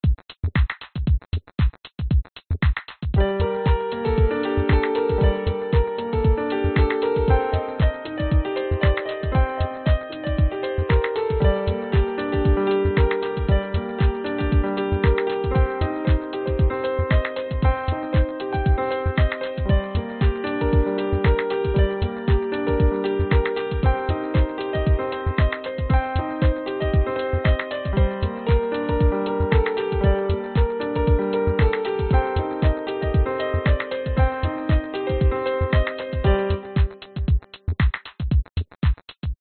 描述：钢琴与鼓组的现场录音。
Tag: 古典 钢琴